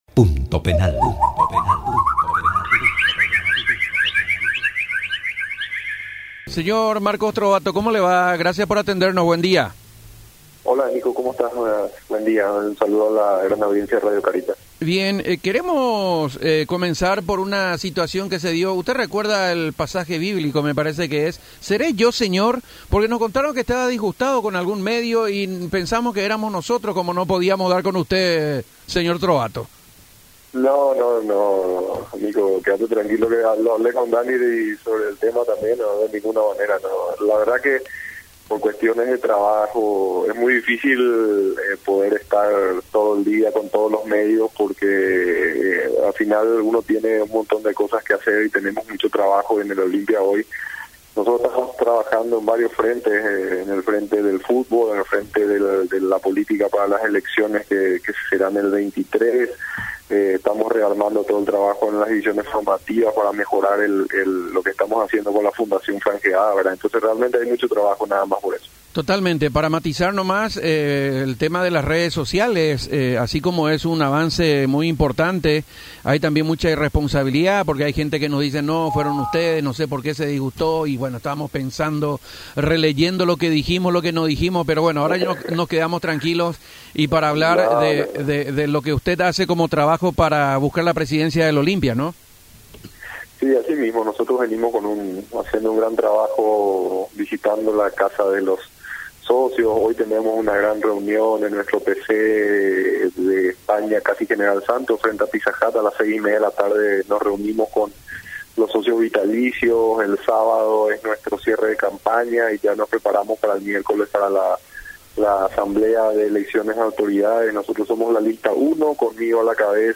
hablando en Cáritas.